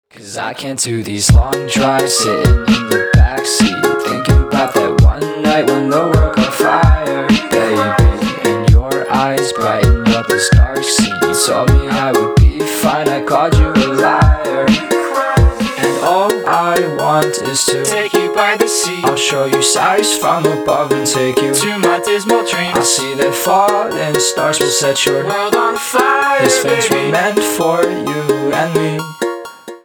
Рок Металл
спокойные